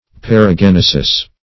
paragenesis - definition of paragenesis - synonyms, pronunciation, spelling from Free Dictionary
Paragenesis \Par`a*gen"e*sis\ (p[a^]r`[.a]*j[e^]n"[-e]*s[i^]s),